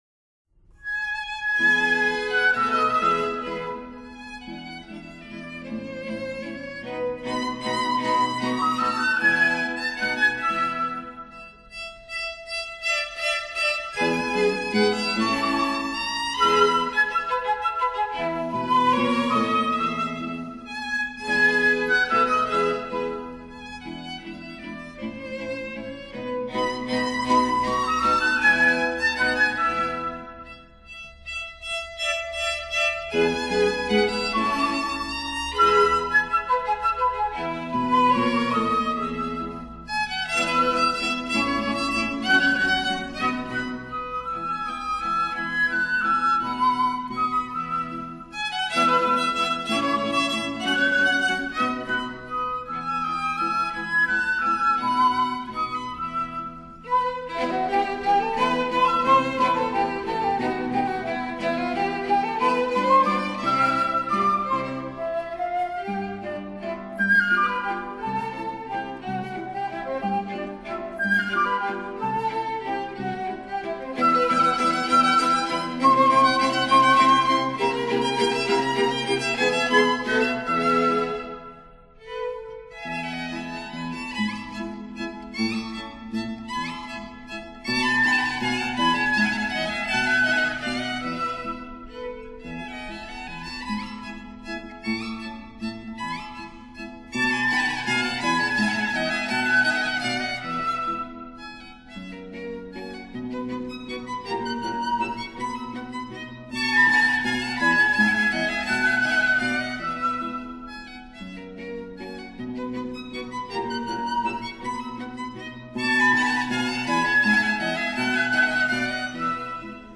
for 2 violins, flute & guitar